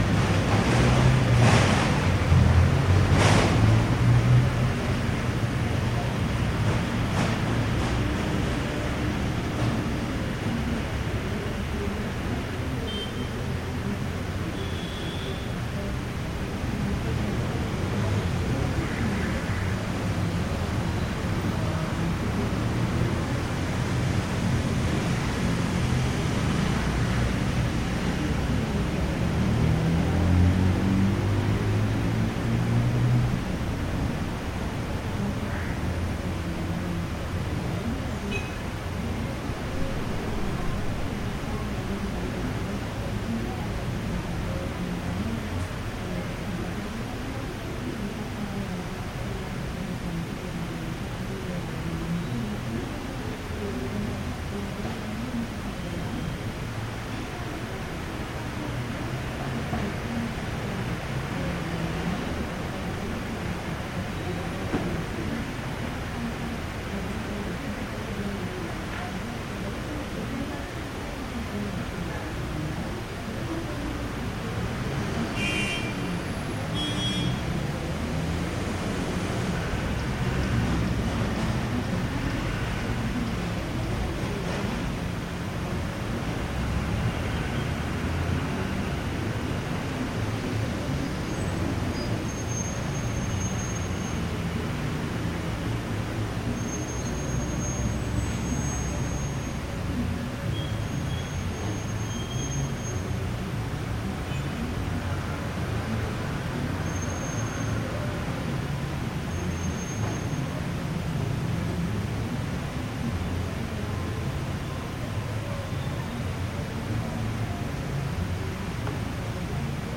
标签： 背景声 音场 氛围 环境 背景 氛围 一般-noise 气氛
声道立体声